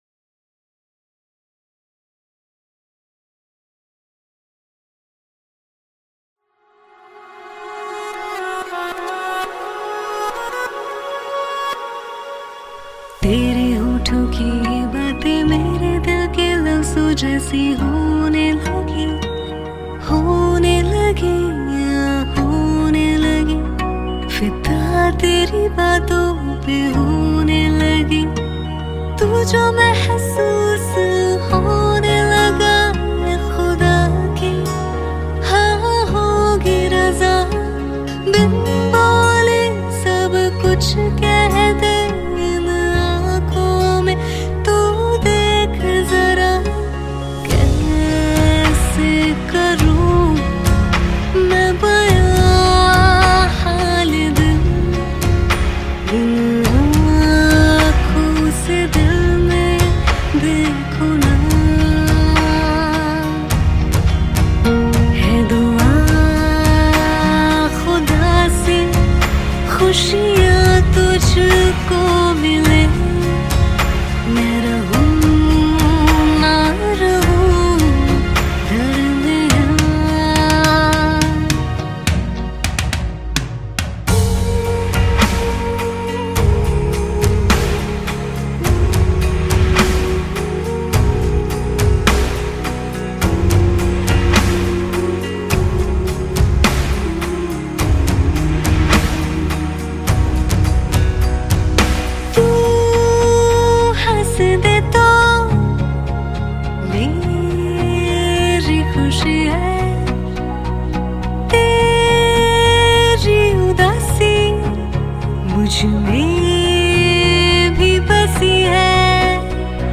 Studio Series